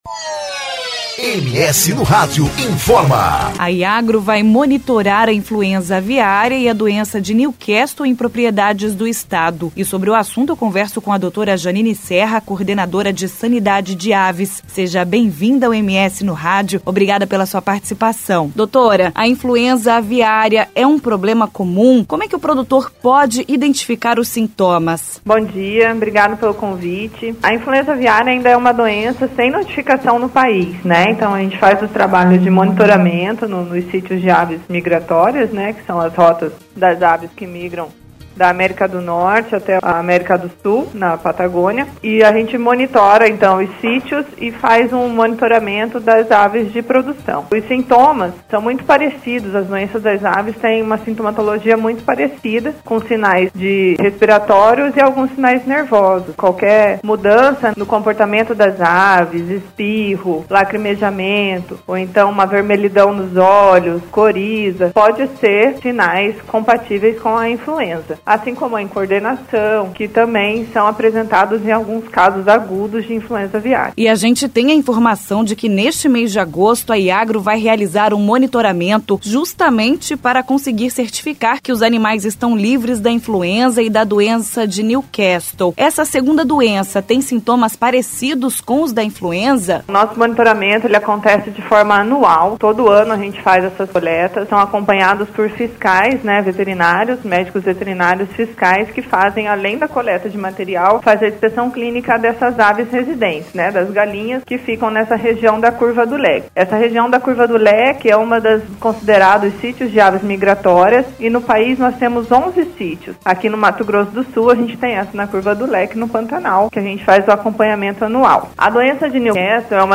Entrevista: Iagro vai monitorar influenza aviária em MS